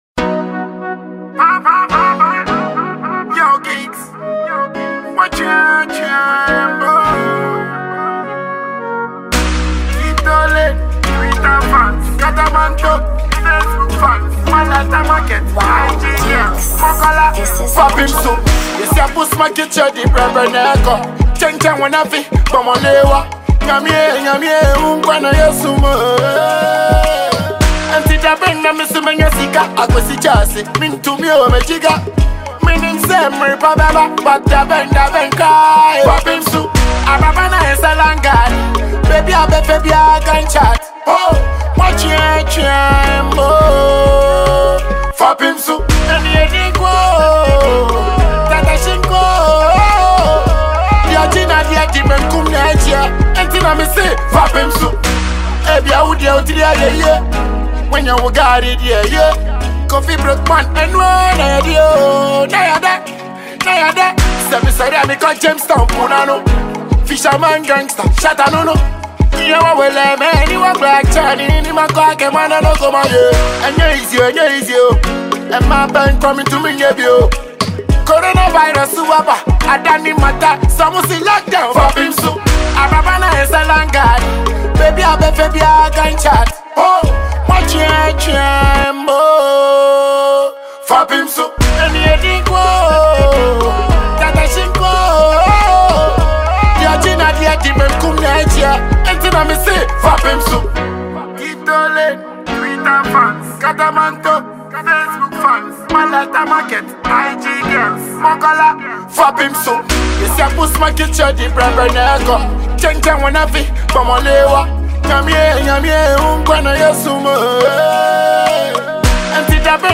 Dancehall
inspirational song